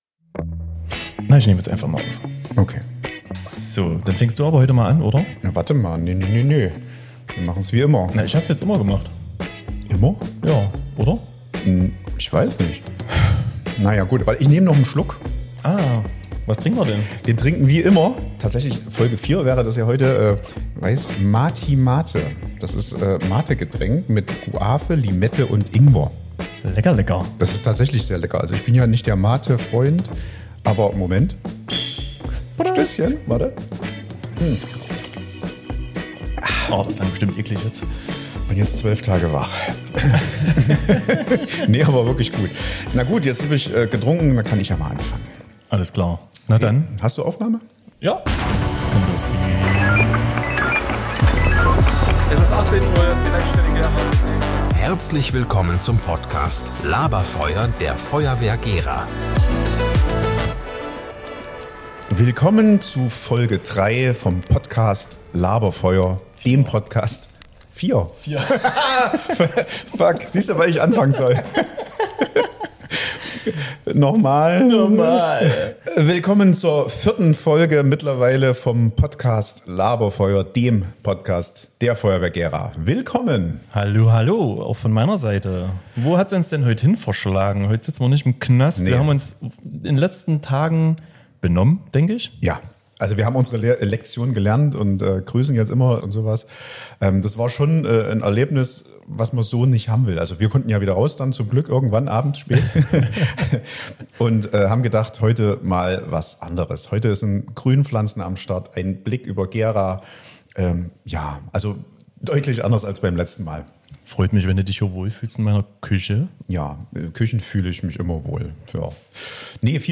Beschreibung vor 1 Woche  Podcast Folge 4 – Back to Basics Diesmal ganz klassisch: Keine Feuerwache, kein besonderes Setting – einfach wir, die Küche und ein ehrliches Gespräch im LABER-FEUER. Wir nehmen euch mit durch unsere festen Kategorien, sprechen über prägende Einsätze aus dem vergangenen Monat, in denen die Rolle von Ersthelfern eine große und wichtige Rolle spielt und werfen einen Blick auf aktuelle Gefahren, die oft unterschätzt werden.